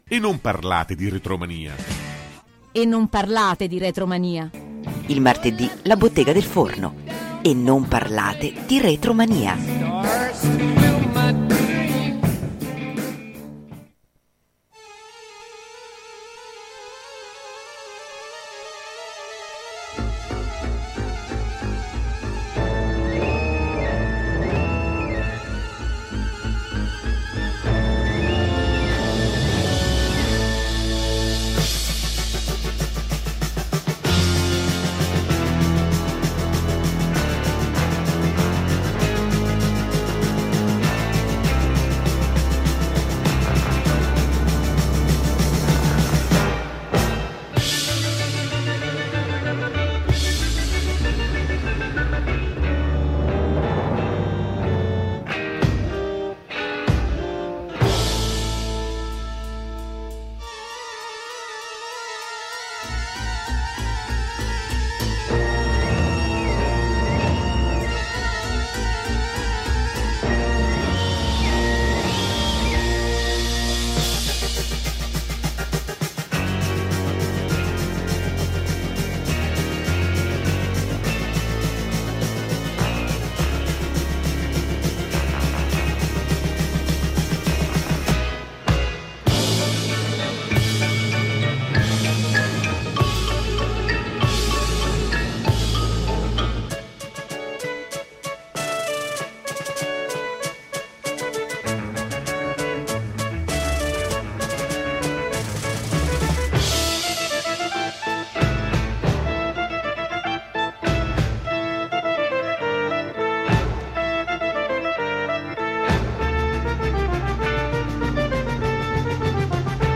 Intervista-Rock-me-Amadeus.mp3